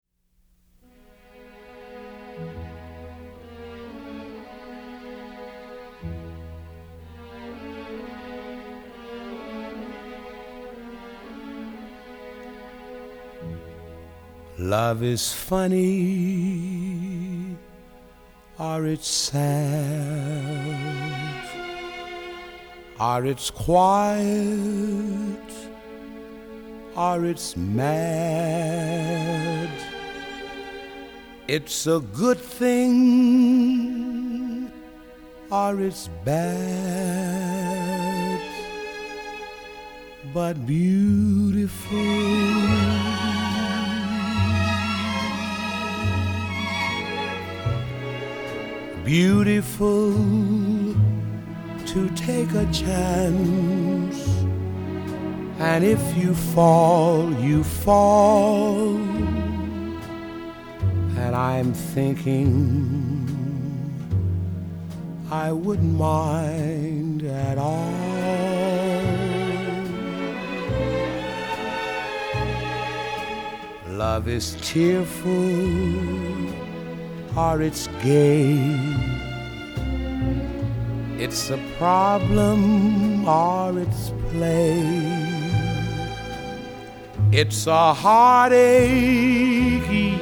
用深情無比的嗓音融化您的心！
採用原始類比母帶以最高音質之 45 轉 LP 復刻！